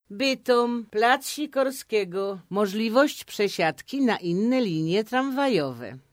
• 14.05.2014 r. W nowych wagonach razem z pasażerami jeździ Pani Joanna Bartel, która zgodziła się  udzielić swojego głosu dla systemu informacji pasażerskiej.